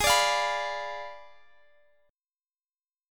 Abdim7 Chord
Listen to Abdim7 strummed